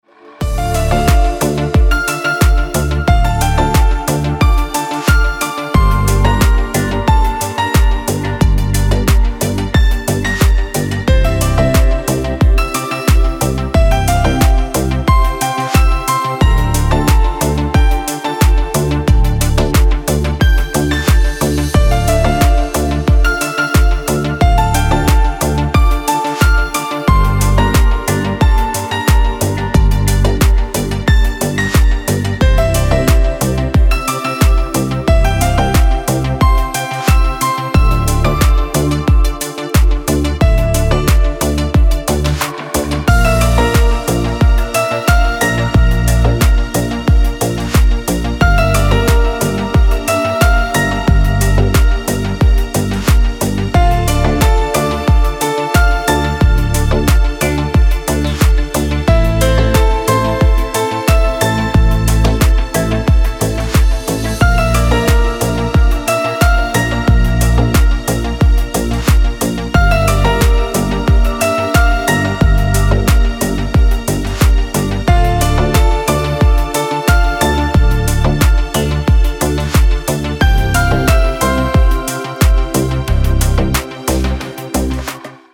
Лиричная мелодия